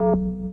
sfx_failure.ogg